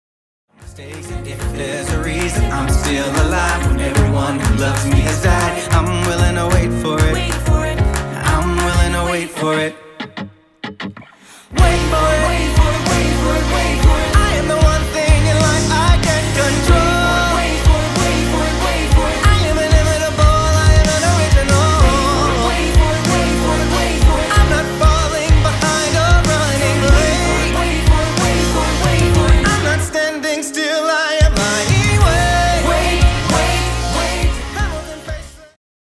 Contemporary Musical Theatre
Contemporary-Musical-Theatre.mp3